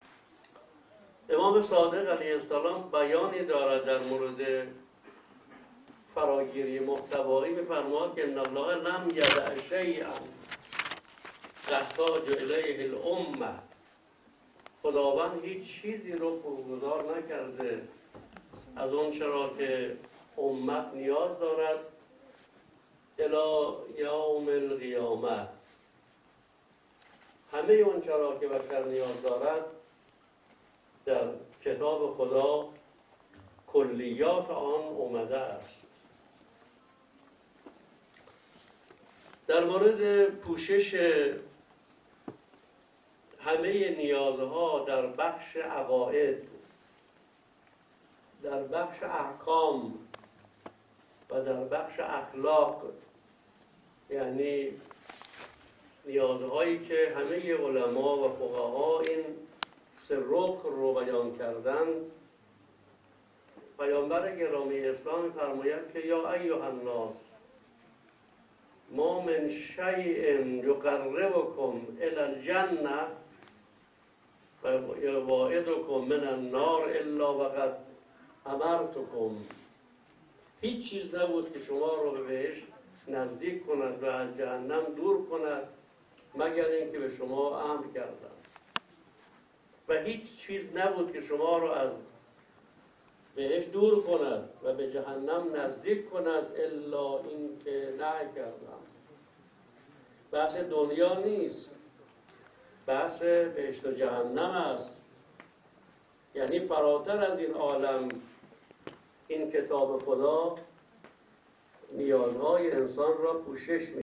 حجت‌الاسلام سعیدی بیان کرد:
اصالت الهی بودن از ویژگی‌های برجسته قرآن است / افرادی با نام فلسفه نکاتی دور از واقعیت را مطرح می‌کنندبه گزارش خبرنگار ایکنا، مراسم اختتامیه مسابقات سراسری قرآن بانوان شاغل، همسران و فرزندان کارکنان نیروهای مسلح به میزبانی ارتش جمهوری اسلامی ایران امروز، شنبه، 27 آذرماه در مرکز همایش‌های کوثر سازمان عقیدتی ـ سیاسی ارتش برگزار شد.